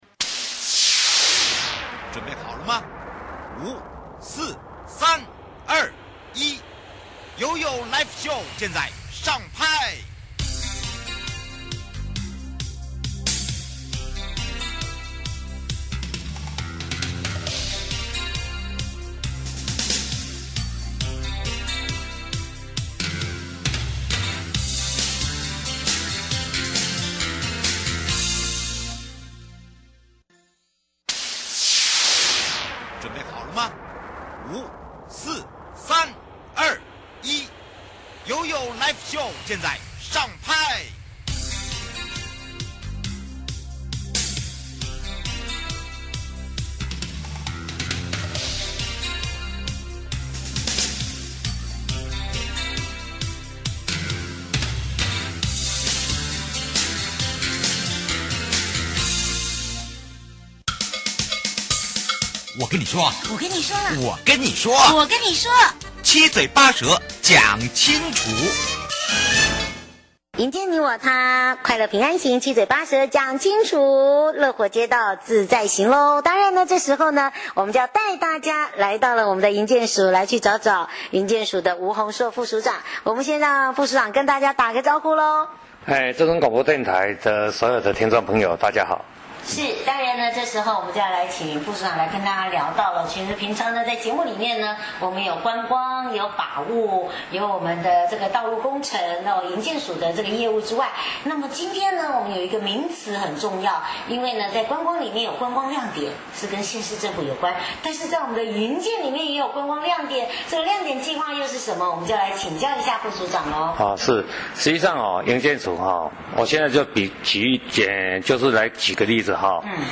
受訪者： 營建你我他-快樂平安行-七嘴八舌講清楚- 重視行人通行空間保護行人安全的理念非常重要是基於什麼樣的想法,開始想跟大家宣導這個觀念,請問公共通行權的議題是怎麼發想出來,提升道路品質計畫,如何配合現在政府推動的政策可以跟我們分享? 節目內容： 內政部營建署吳宏碩副署長 (第二集)